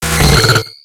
Cri de Loupio dans Pokémon X et Y.